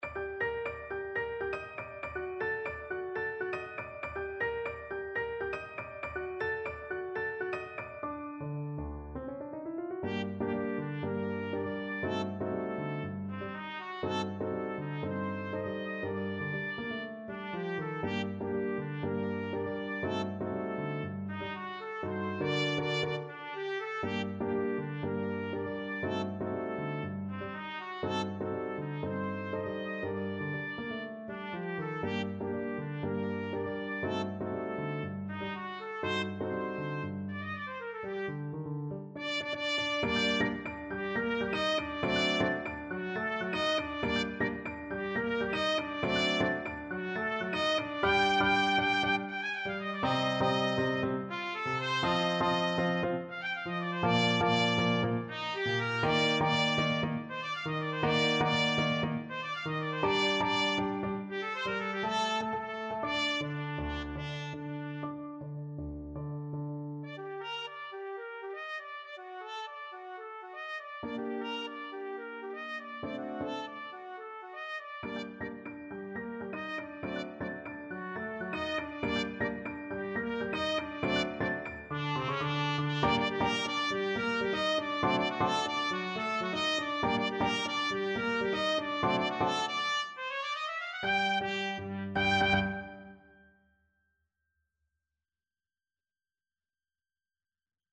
A firey salsa-inspired piece.
4/4 (View more 4/4 Music)
Energico =120
Jazz (View more Jazz Trumpet Music)